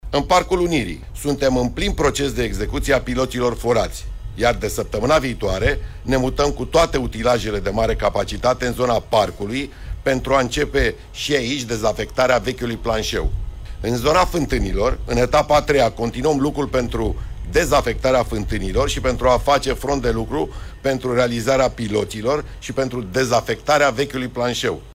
Daniel Băluță, primarul Sectorului 4: „În Parcul Unirii suntem în plin proces de execuție a piloților forați”